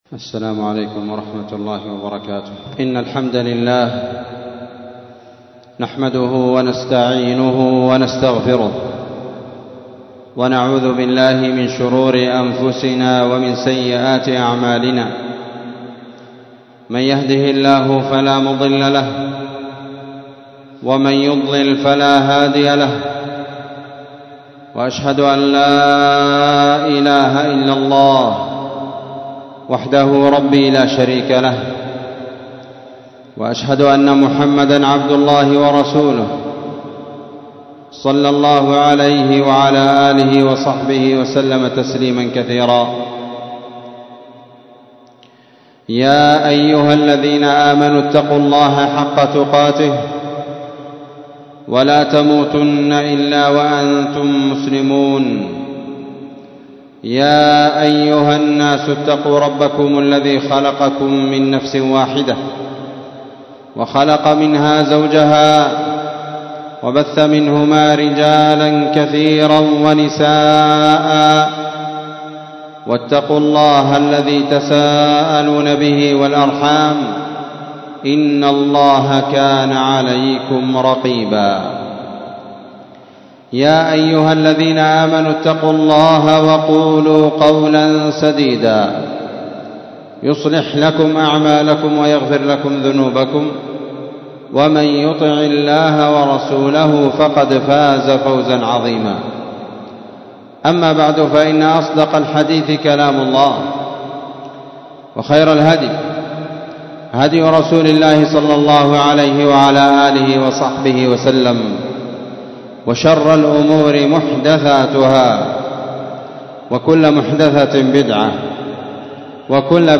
خطبة جمعة
مسجد المجاهد- تعز - اليمن